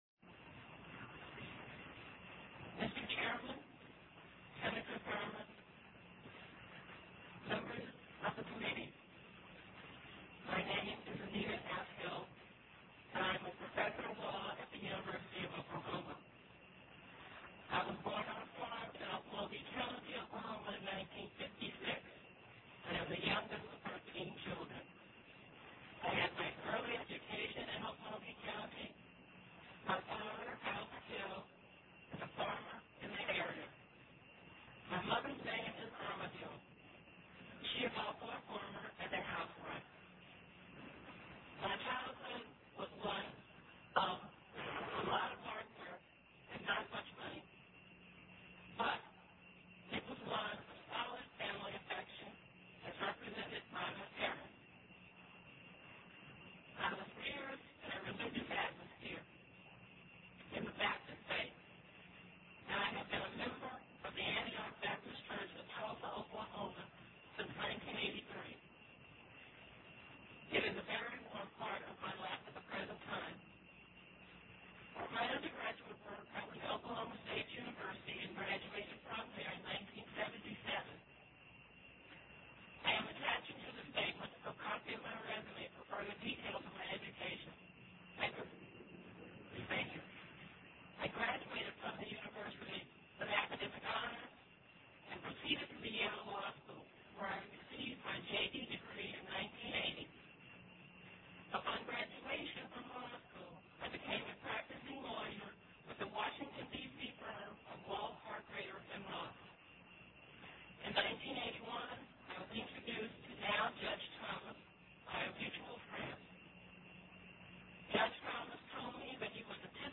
Opening Statement to the Senate Judiciary Committee